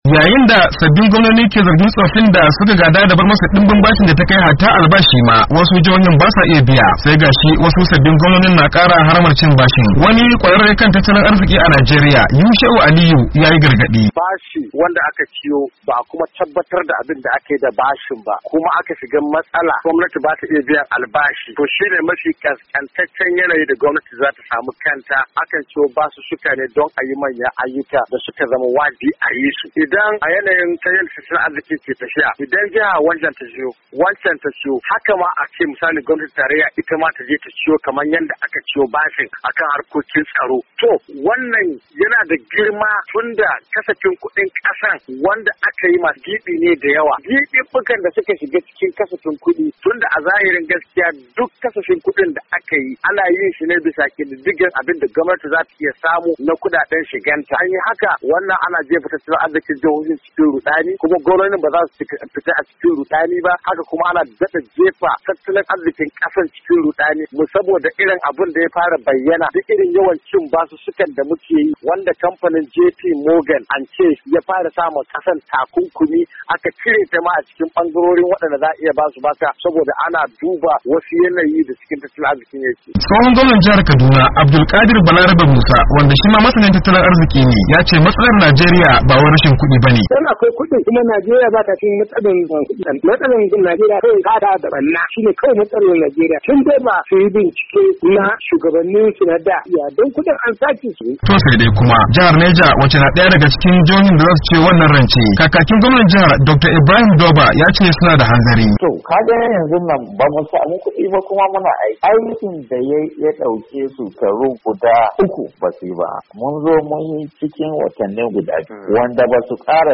Shima tsohon gwamnan jihar Kaduna Alhaji Abdul kadir Balarabe Musa masani harkokin tattalin kasa yace matsalar Najeriya ba wai rashin kudi bane wanda zaisa ta rika cin bashi. Ga dai abinda tsohon gwamnan ke cewa.